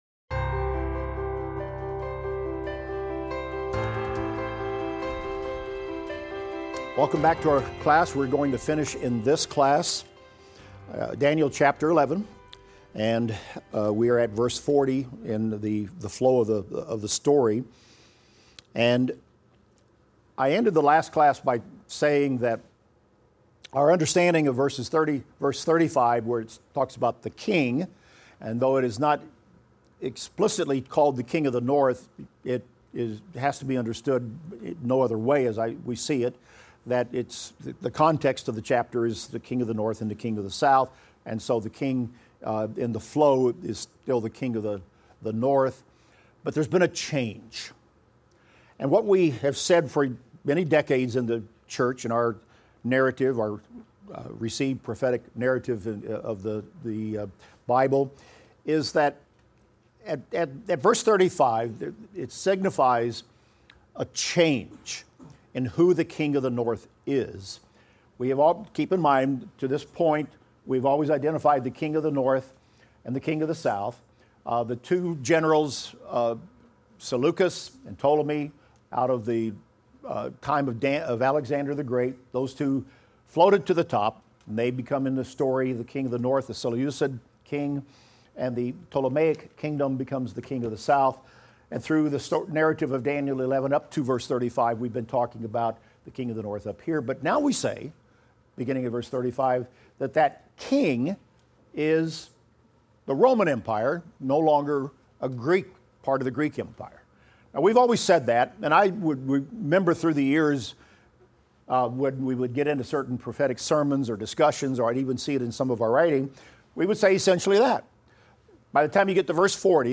Daniel - Lecture 21 - audio.mp3